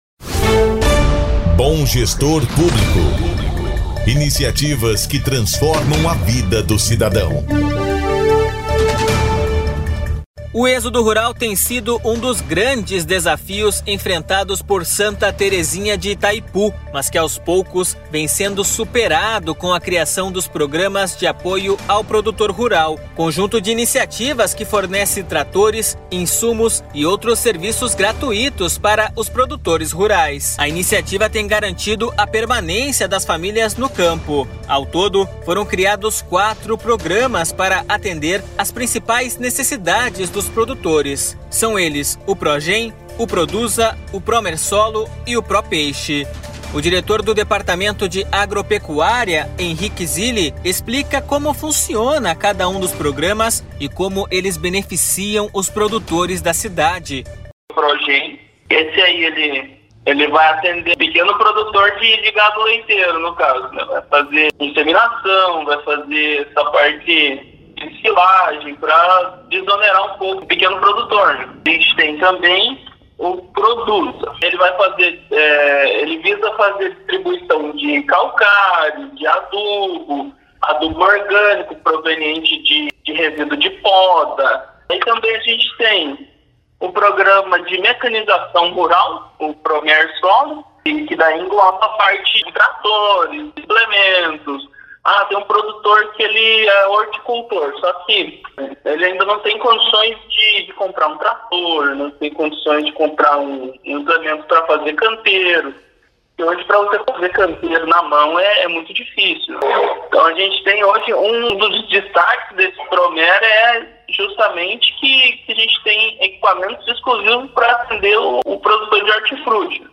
Fique com a reportagem do Sindicato dos Auditores Fiscais da Receita do Estado do Paraná (Sindafep).